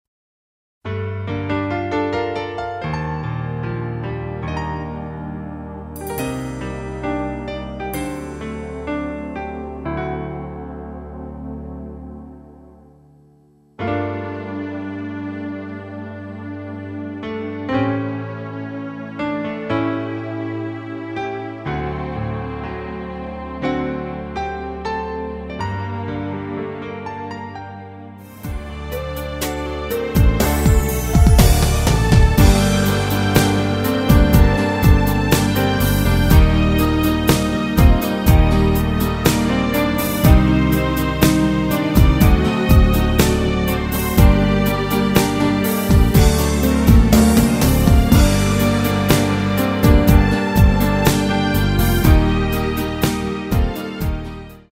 원키에서 (-2)내린 MR 입니다. 발매일 2005년 11월 09일 키 Gm 가수